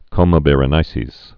(kōmə bĕrə-nīsēz)